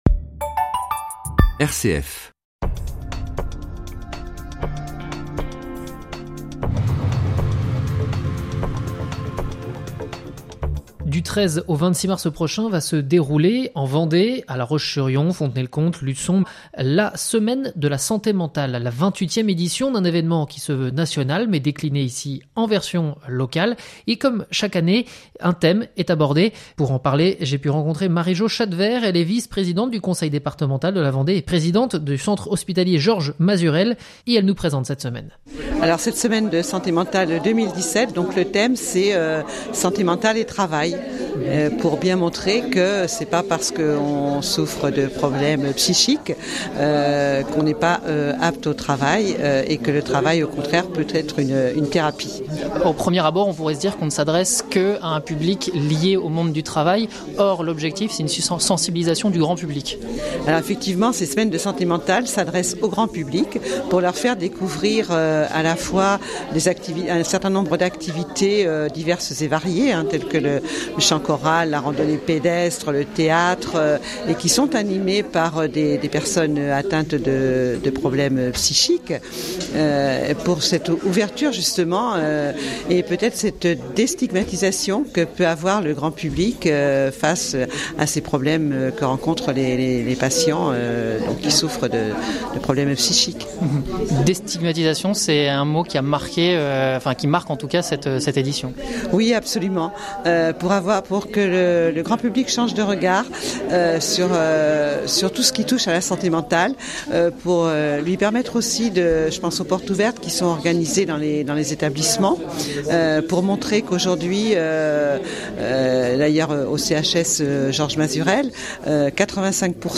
Il est ce matin l?invité de RCF Vendée